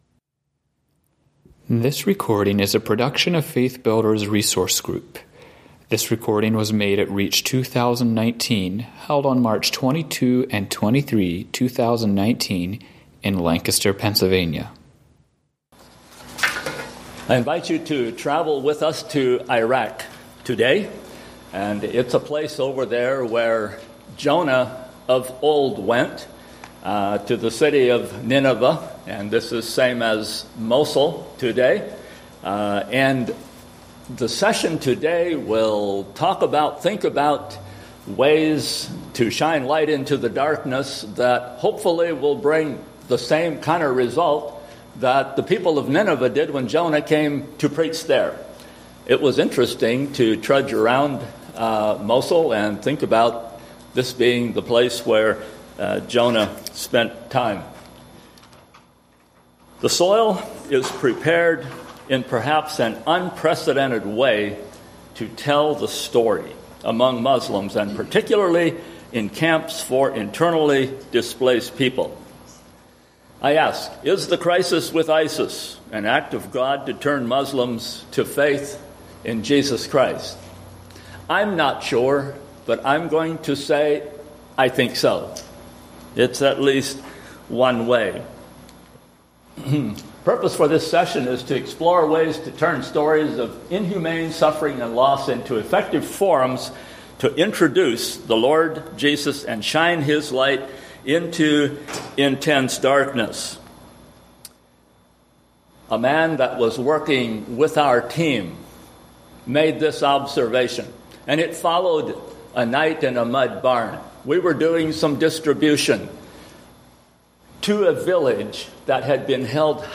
Home » Lectures » Introducing Jesus in an IDP Camp